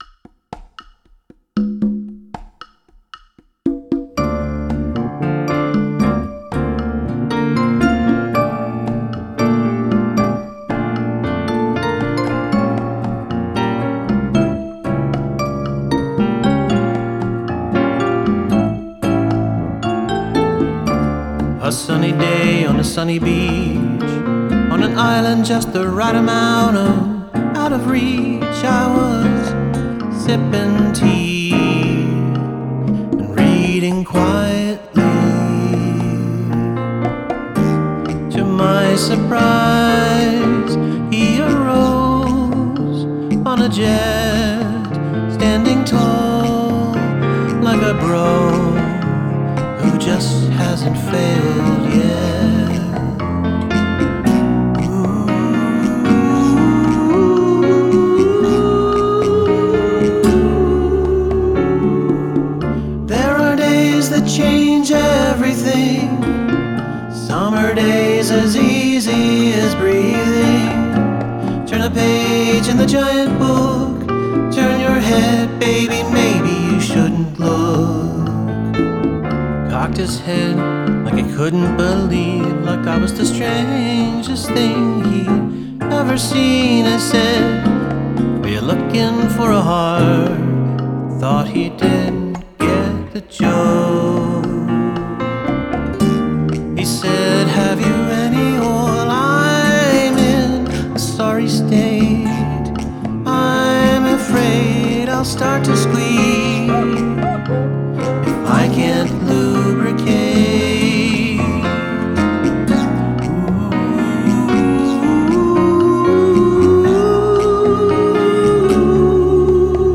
finger-picked nylon-string acoustic
so smooth, the performances and production here are superb.